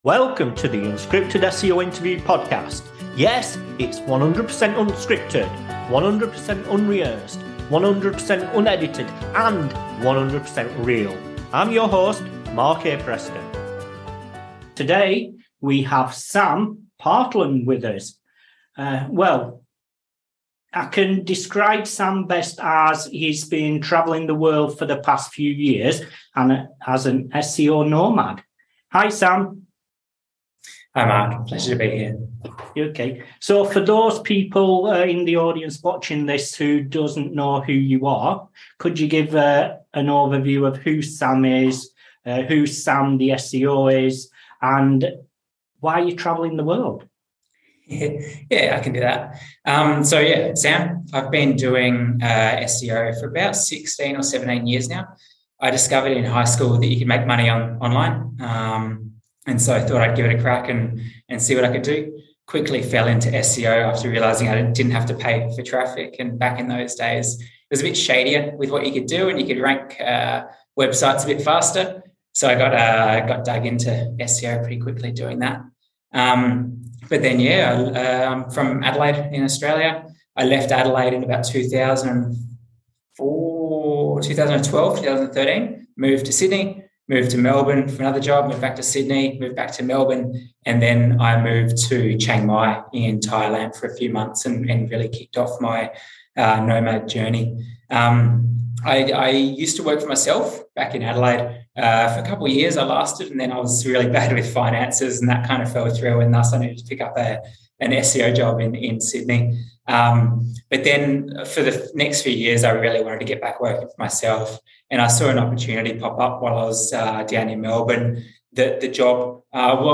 The Unscripted SEO Interview Podcast